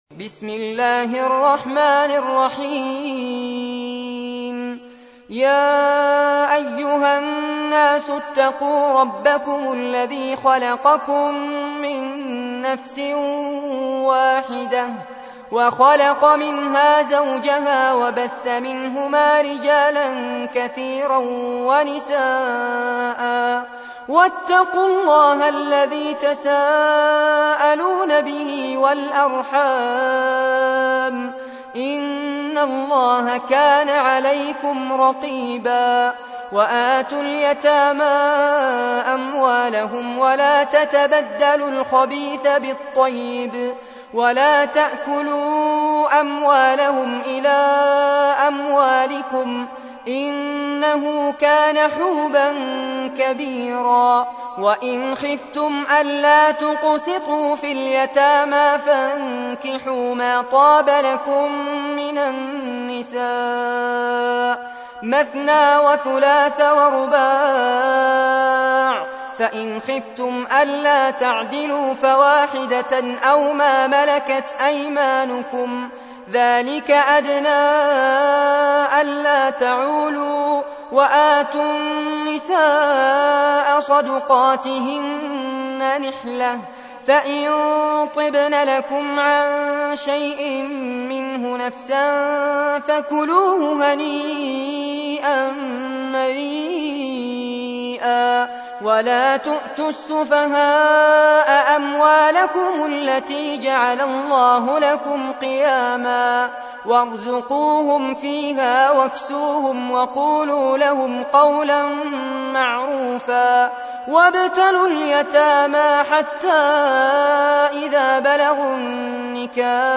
تحميل : 4. سورة النساء / القارئ فارس عباد / القرآن الكريم / موقع يا حسين